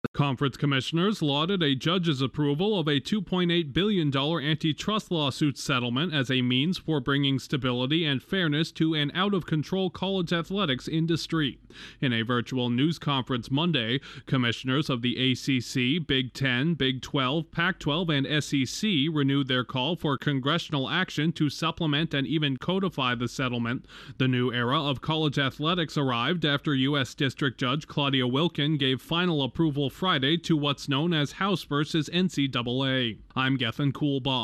Major conference commissioners across college sports are praising a new groundbreaking settlement over student-athlete compensation. Correspondent